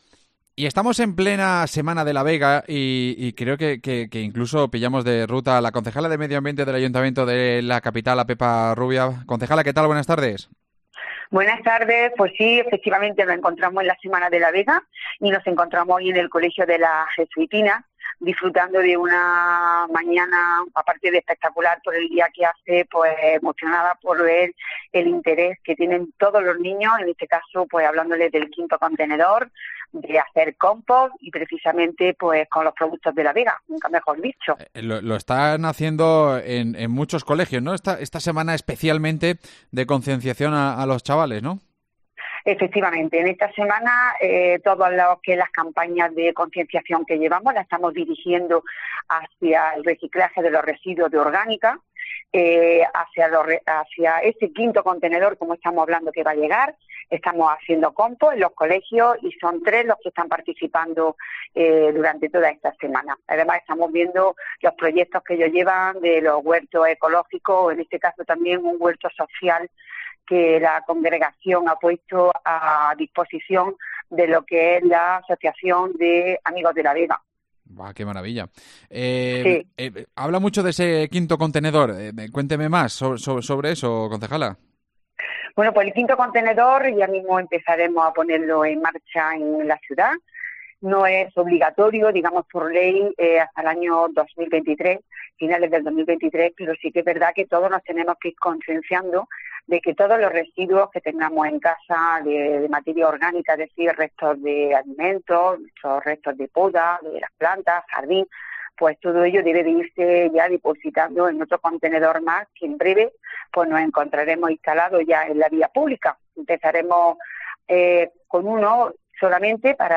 Sobre este y más temas hablamos con la concejala de medio ambiente, Pepa Rubia